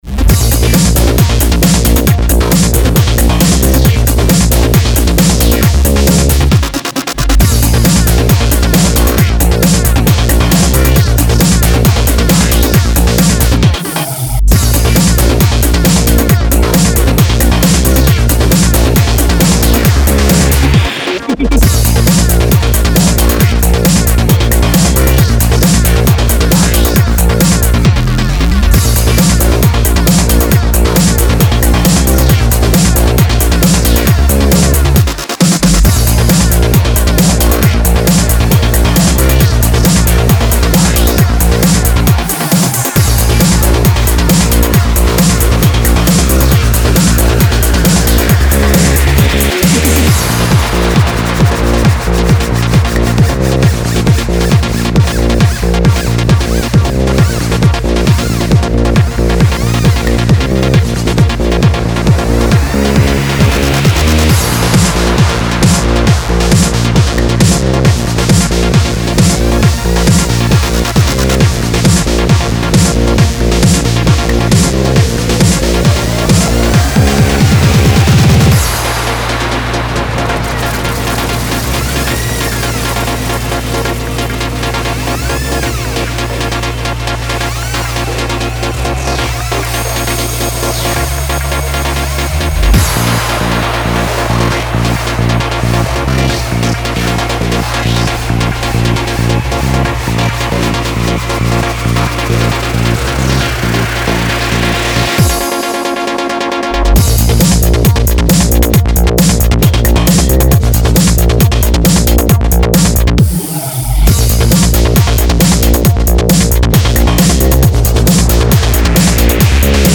Genres Breaks Psy-Breaks